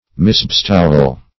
Misbestowal \Mis`be*stow"al\, n.